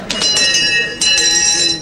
• WAKE UP BELL.wav
WAKE_UP_BELL_rzF.wav